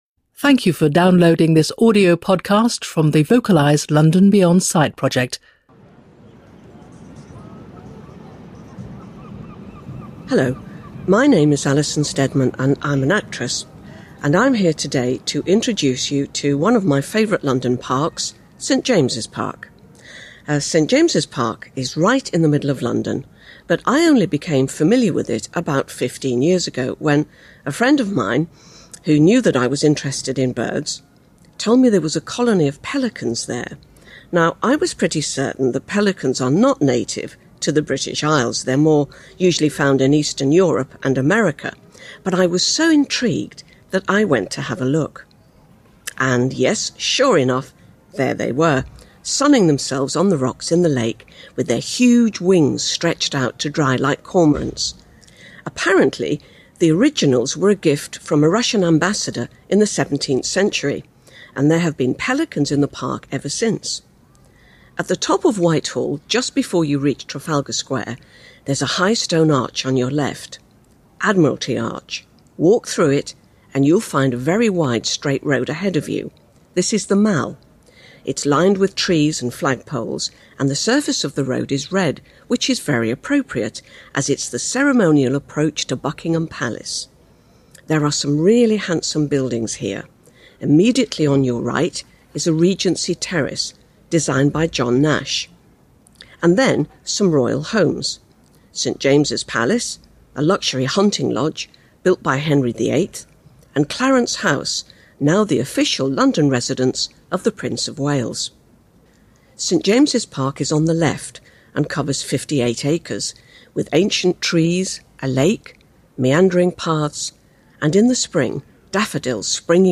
세인트 제임스 공원에 대한 앨리슨 스테드먼의 오디오 설명